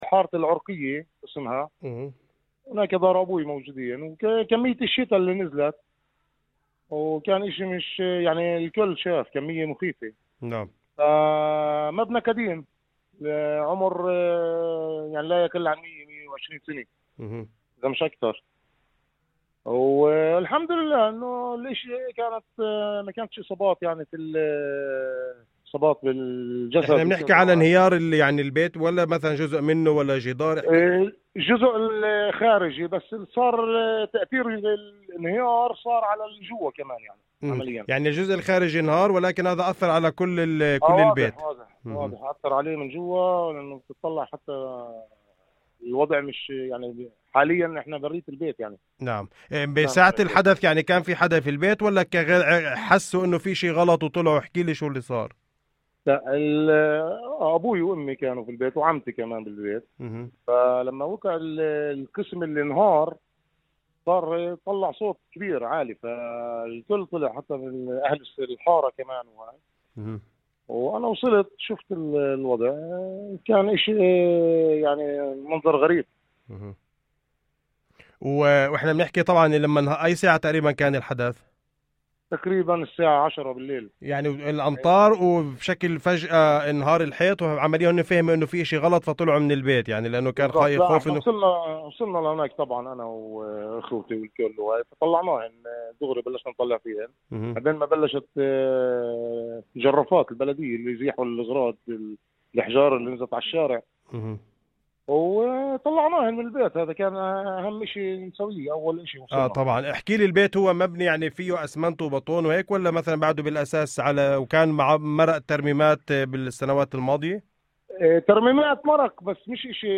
وللحديث عما حصل، كانت لنا مداخلة هاتفية في برنامج "الظهيرة حتى الآن"